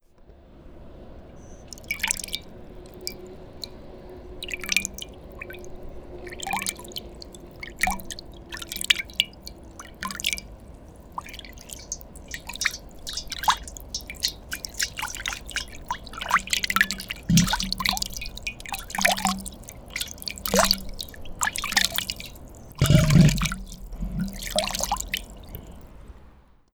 우주_파동.wav